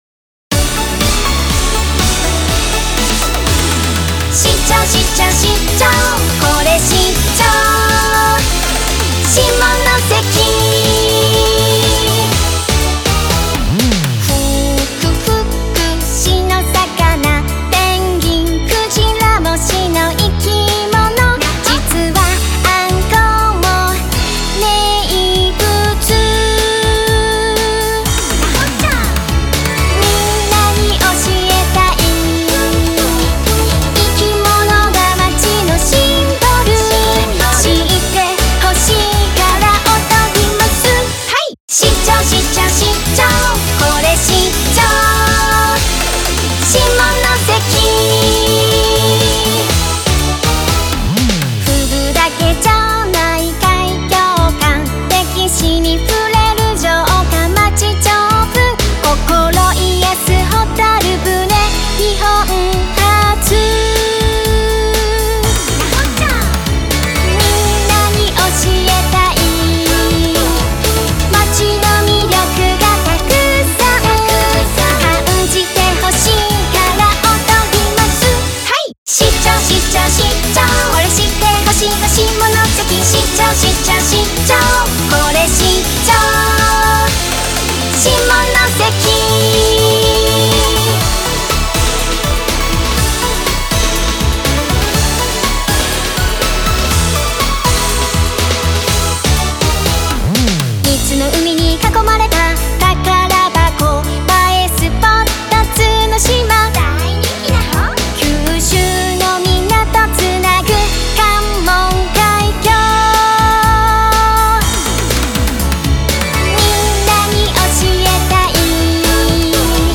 一度聞くと忘れないキャッチーなメロディ。